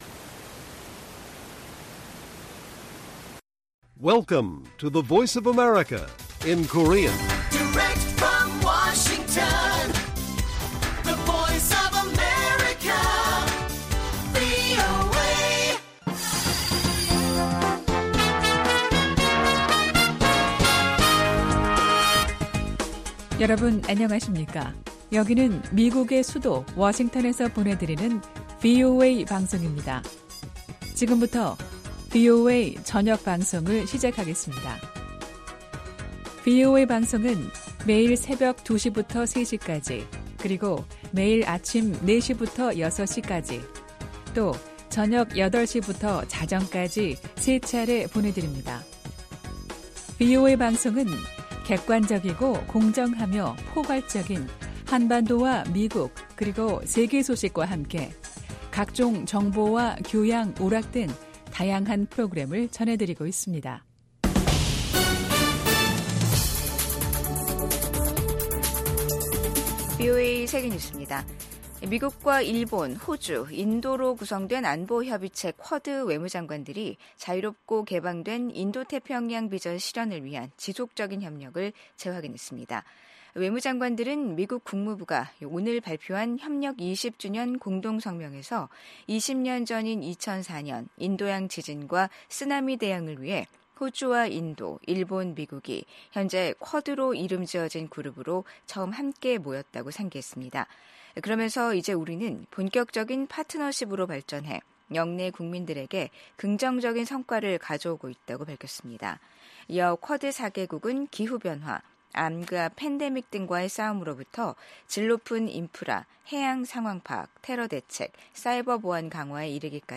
VOA 한국어 간판 뉴스 프로그램 '뉴스 투데이', 2024년 12월 31일 1부 방송입니다. 미국 국방부는 러시아에 파병된 북한군이 쿠르스크에서 벌이는 공격이 별로 효과가 없다고 평가했습니다.